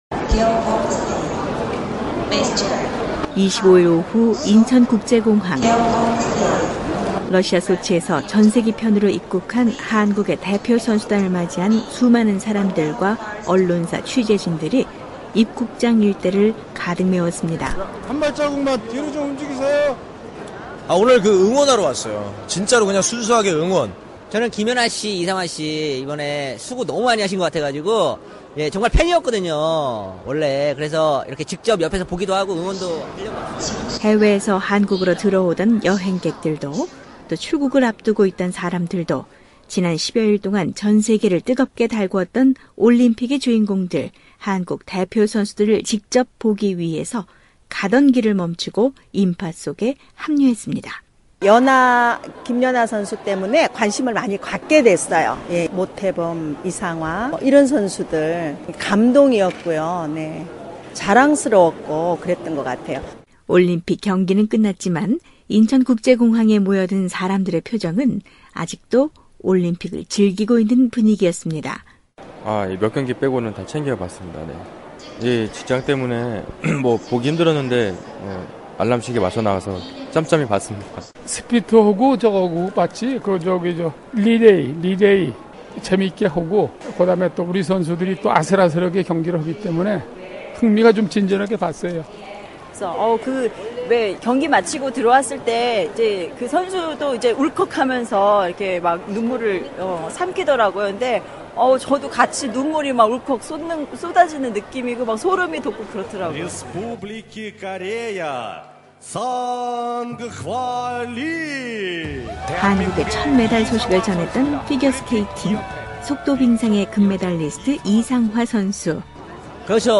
한국사회의 이모저모를 전해드리는 ‘안녕하세요 서울입니다’ 오늘은 러시아 소치에서 돌아온 올림픽선수단을 환영하는 한국사람들의 목소리를 전해드립니다. 금메달 4개를 따내 세계 10위 안에 들겠다는 목표를 세웠던 한국은 이번 대회에서 금메달3, 은3, 동메달 2개로 종합 13위로 조금은 아쉬운 결과에 머물렀는데요. 25일 오후, 인천국제공항 입국장 일대는 한국대표선수들을 맞이하는 환호와 격려가 넘치는 뜨거운 현장이었습니다.